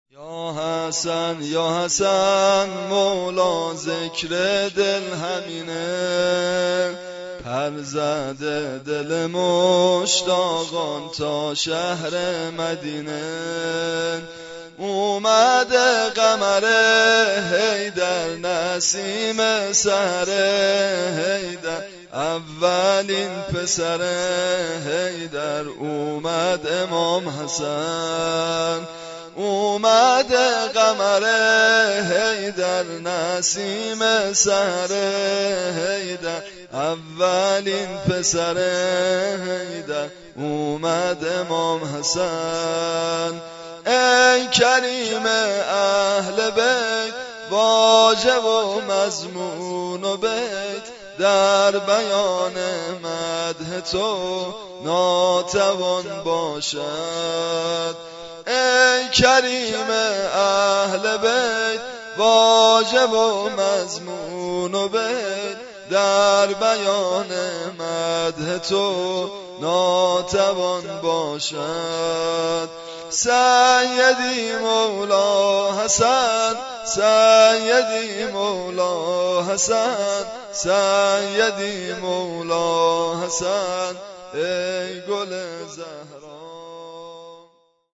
سرود زیبایی دیگر در مورد روز ولادت امام حسن(ع) -( یا حسن یا حسن مولا ، ذکر دل همینه )